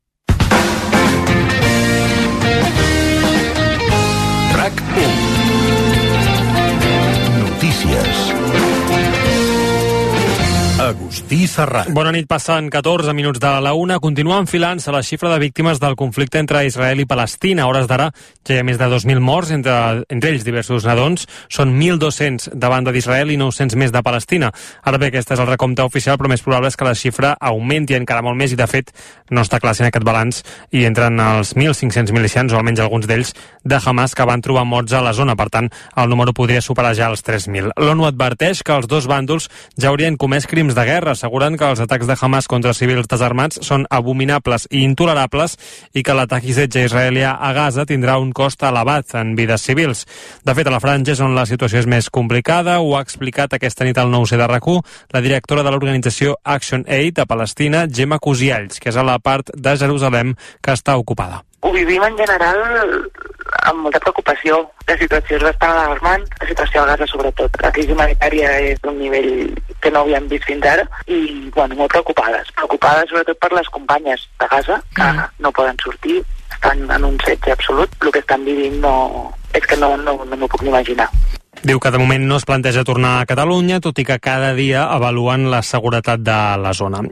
Careta del programa, hora i informació del nombre de morts en el conflicte bèl·lic entre Palestina i Israel, iniciat el dia 7 d'octubre de 2023
Informatiu